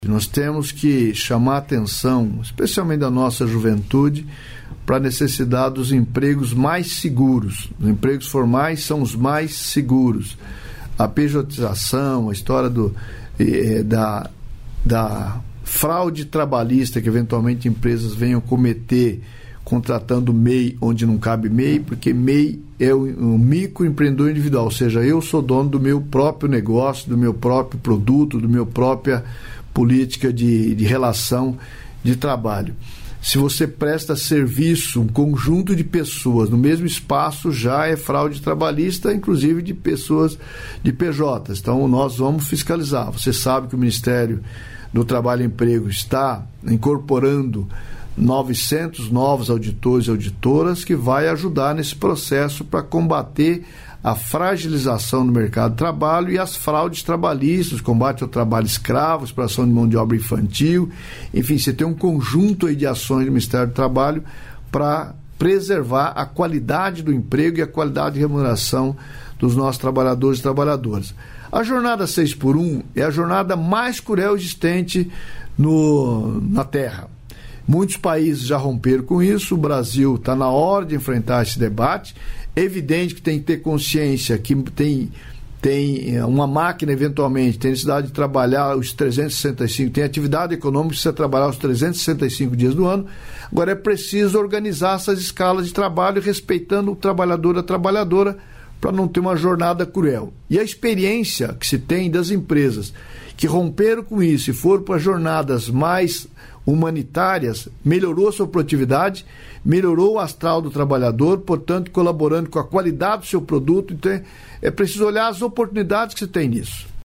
Trecho da participação do ministro do Trabalho e Emprego, Luiz Marinho, no programa "Bom Dia, Ministro" desta quinta-feira (13), nos estúdios da EBC, em Brasília.